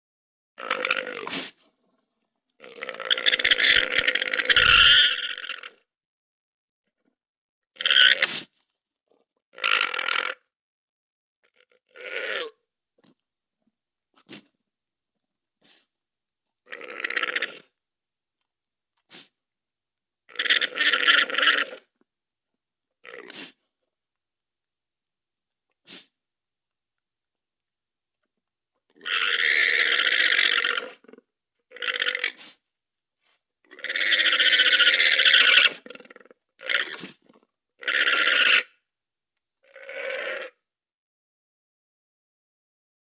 Звуки ламы
Лама издает вот такой звук